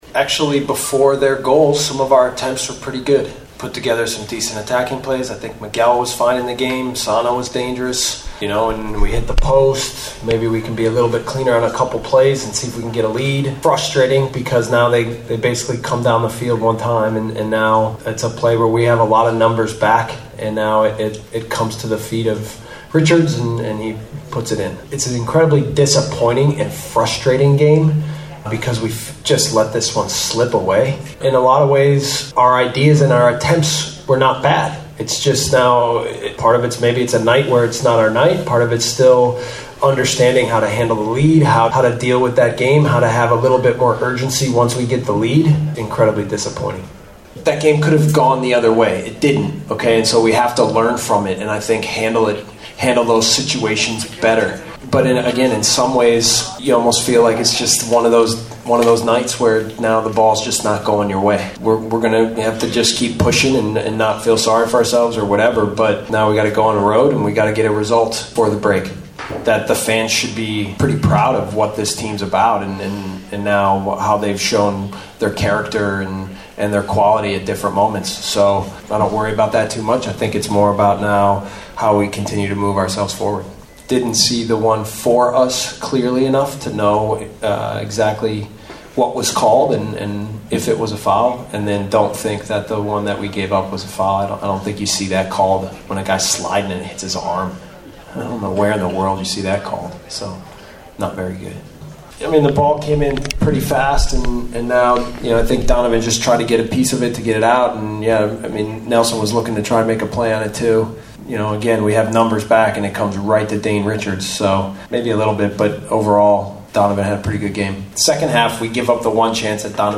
Interviste: